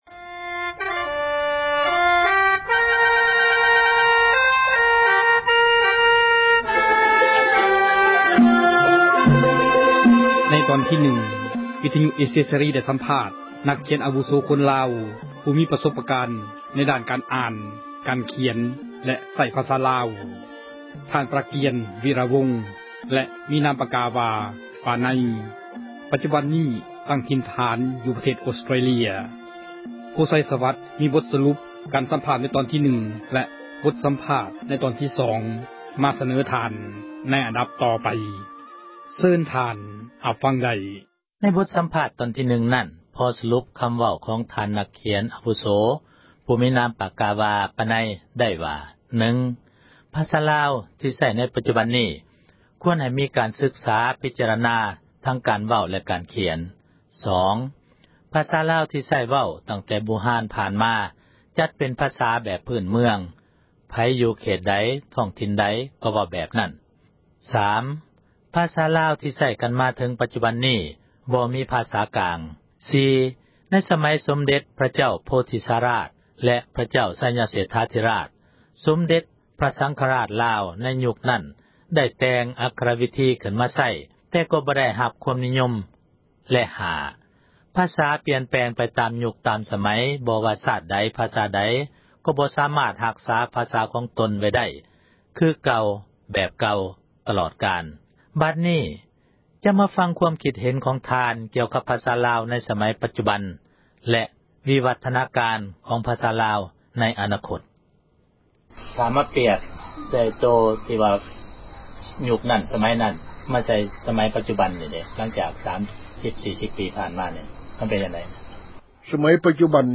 ສັມພາດນັກຂຽນລາວ ທີ່ Australia ຕໍ່